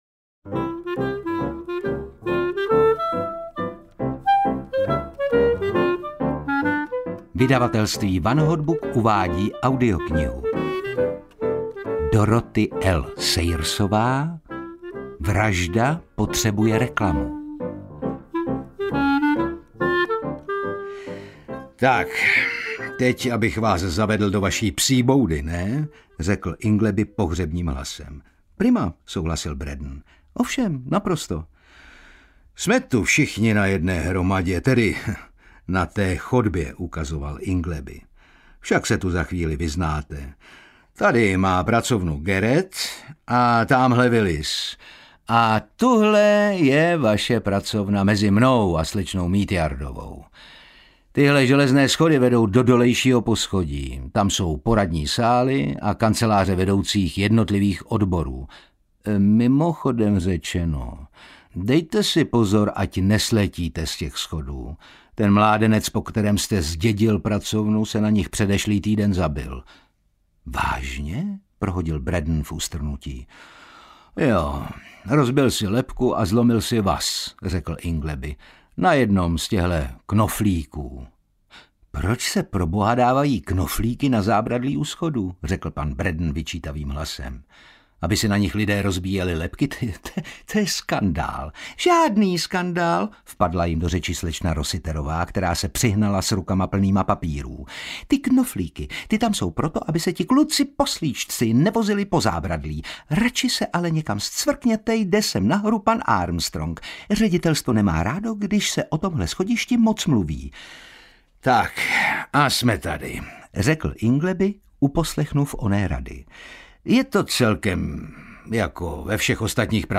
Vražda potřebuje reklamu audiokniha
Ukázka z knihy